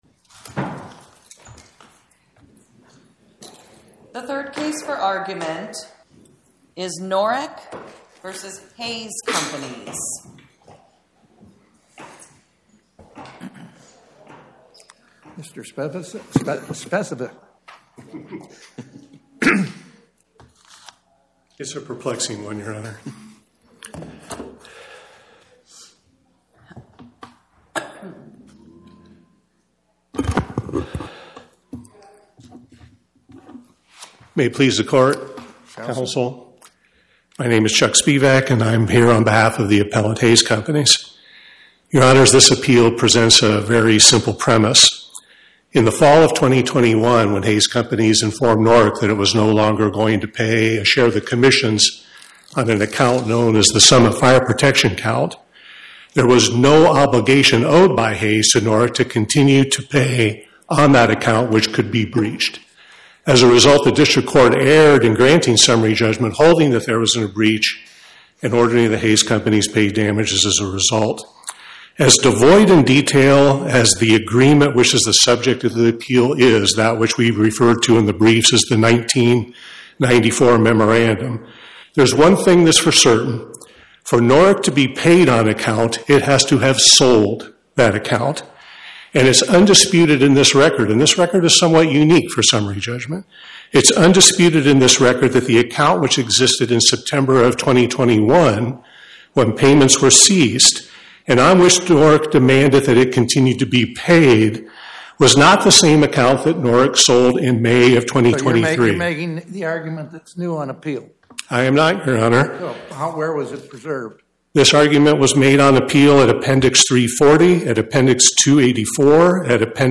My Sentiment & Notes 25-1487: Norick, Inc. vs Hays Companies Podcast: Oral Arguments from the Eighth Circuit U.S. Court of Appeals Published On: Tue Dec 16 2025 Description: Oral argument argued before the Eighth Circuit U.S. Court of Appeals on or about 12/16/2025